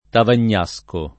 [ tavan’n’ #S ko ]